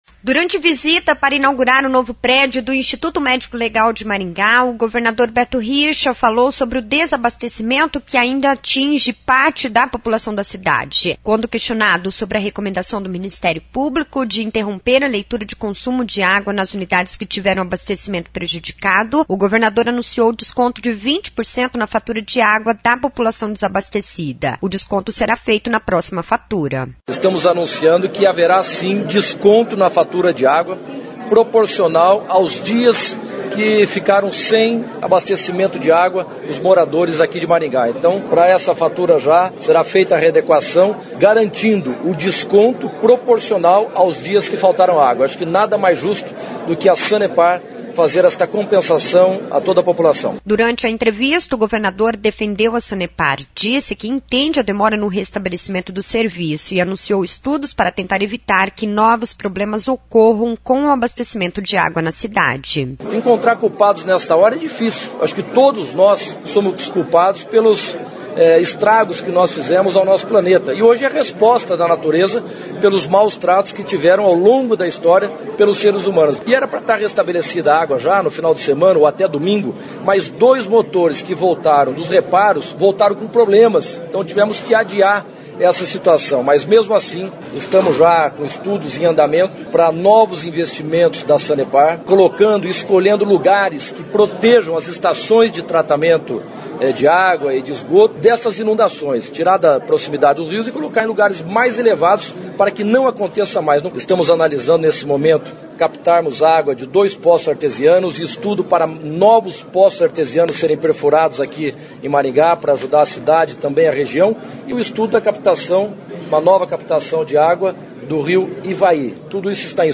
O anunciou foi feito pelo governador Beto Richa, que também falou sobre a perfuração de novos poços artesianos e sobre estudos para captação de água do rio Ivaí